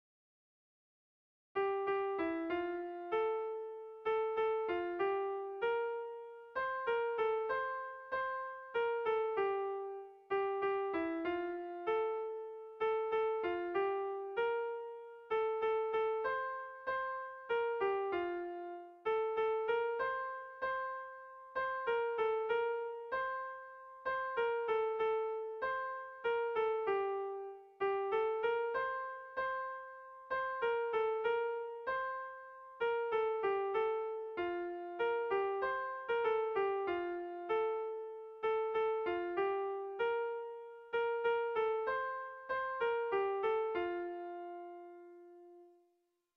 Kontakizunezkoa
Hamarreko handia (hg) / Bost puntuko handia (ip)
A1A2A3BA2